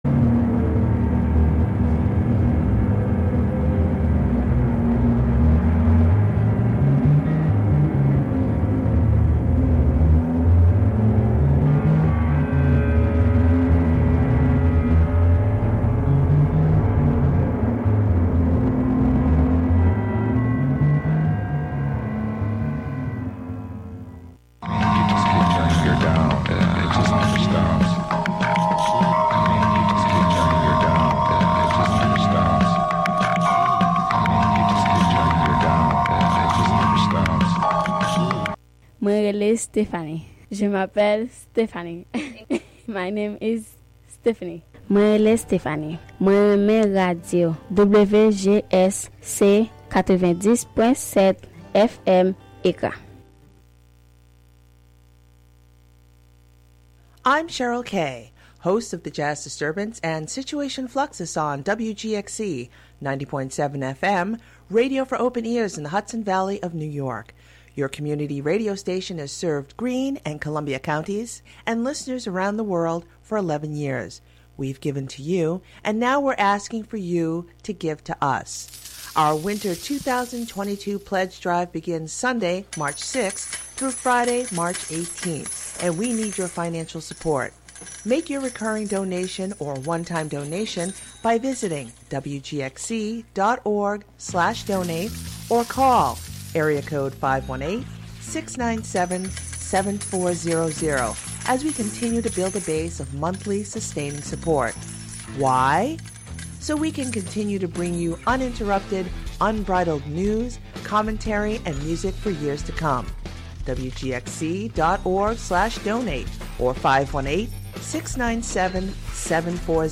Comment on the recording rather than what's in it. Counting down ten new sounds, stories, or songs, "American Top 40"-style.